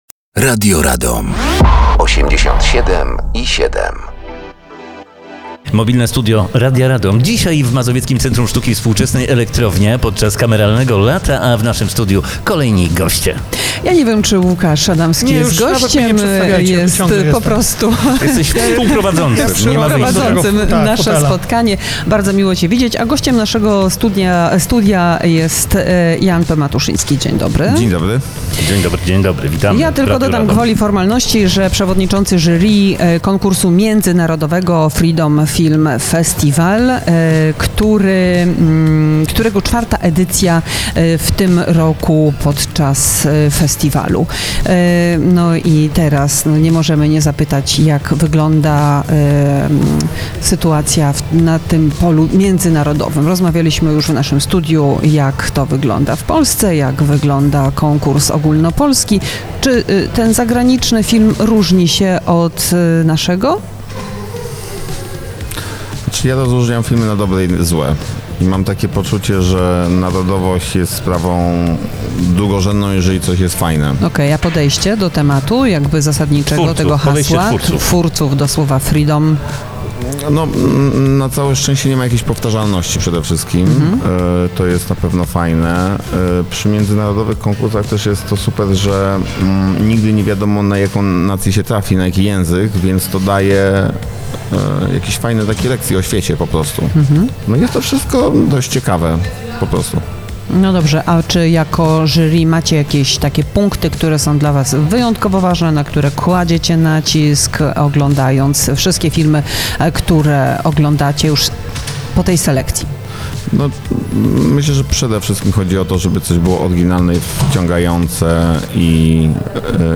Gościem Mobilnego Studia Radia Radom był reżyser Jan P. Matuszyński.